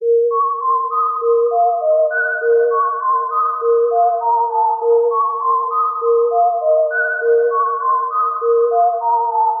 Tag: 100 bpm Trap Loops Synth Loops 1.62 MB wav Key : A